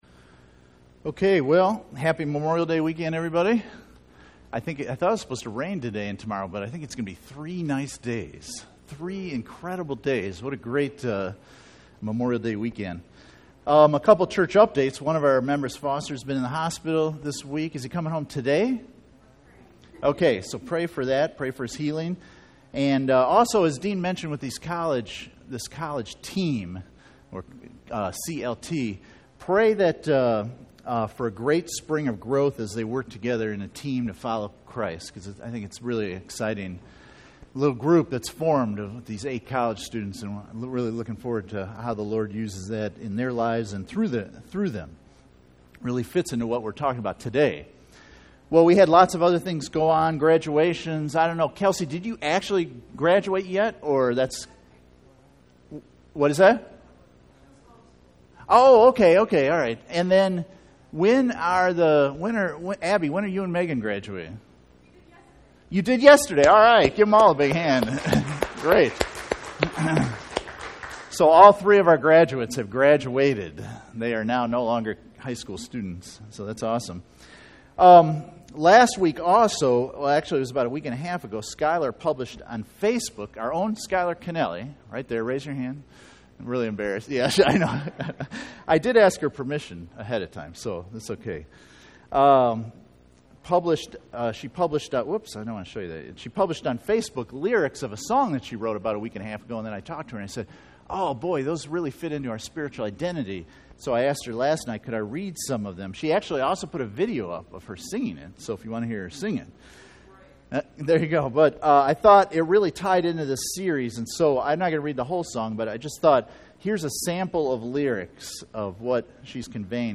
Spiritual Identity Service Type: Sunday Morning %todo_render% « Spiritual Identity